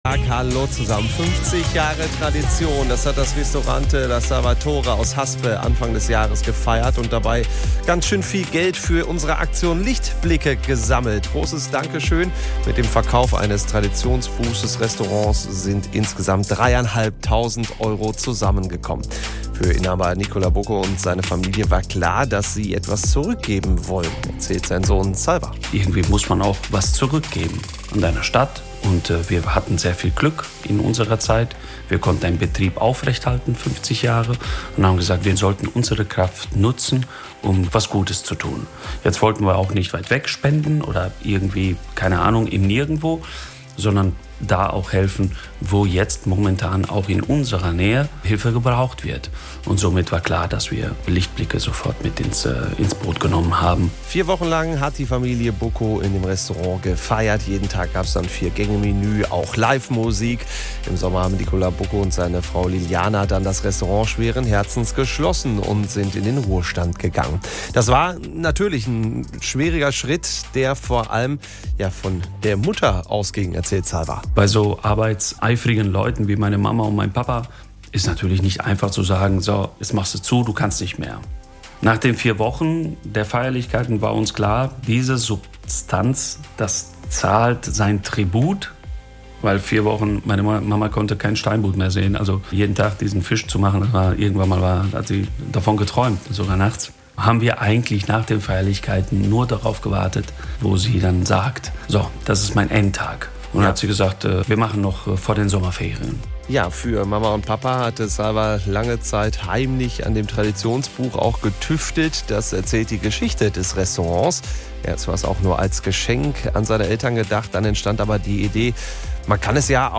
HIER DER MITSCHNITT AUS DER SENDUNG